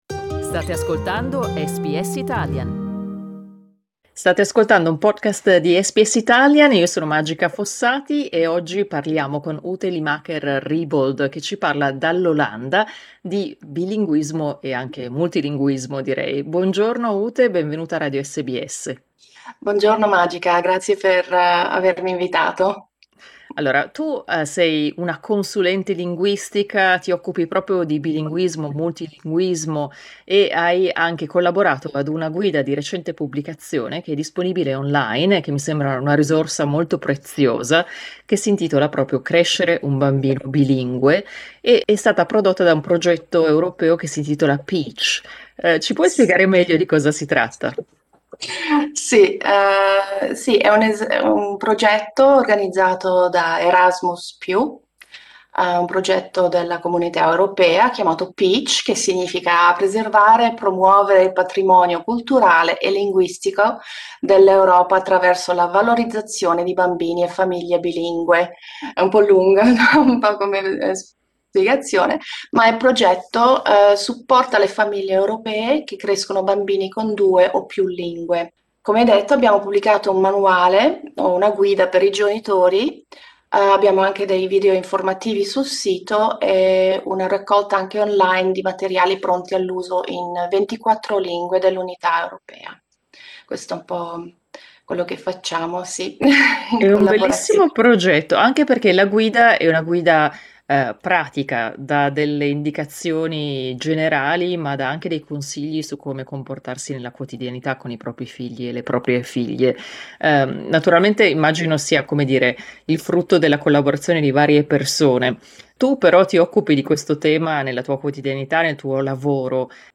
Dall'Europa arriva un utile manuale pratico per genitori che cercano di insegnare più di una lingua ai propri figli e alle proprie figlie. Ne parliamo con una delle coautrici